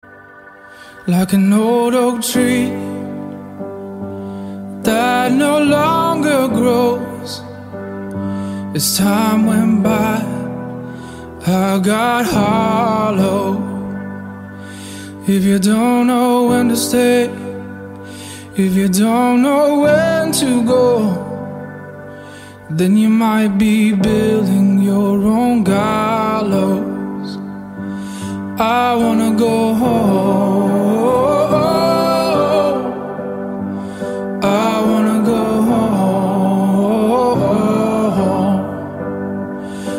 кантри , фолк , поп